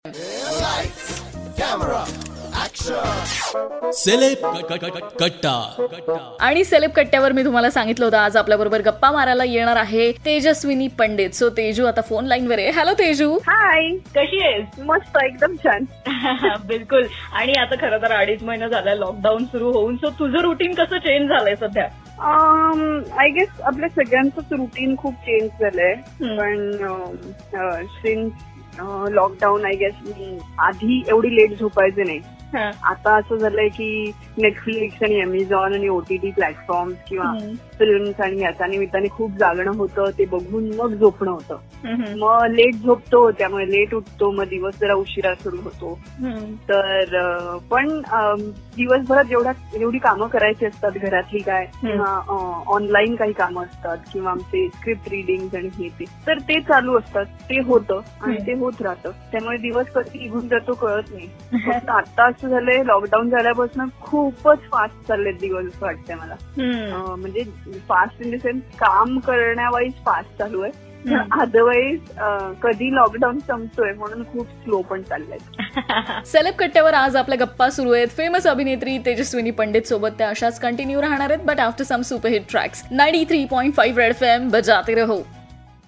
In this interview She shared her lockdown routine at home..